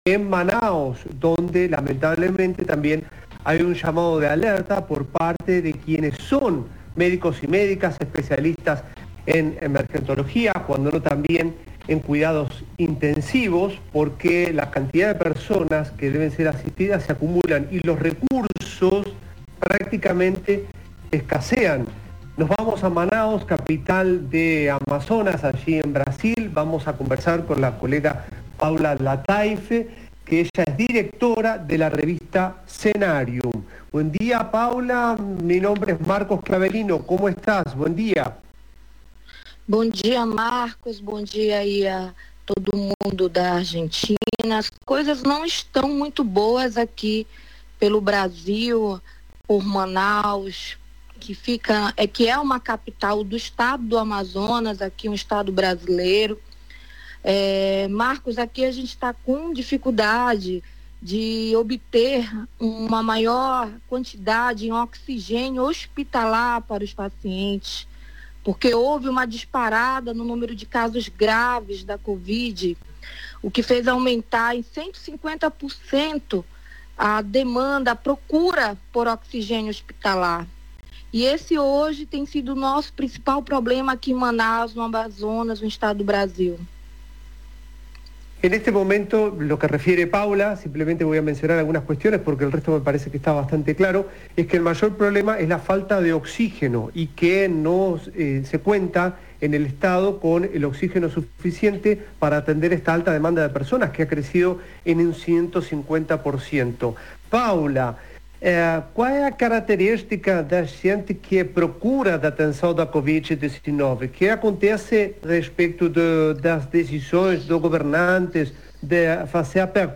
Entrevista-REVISTA-CENARIUM.mp3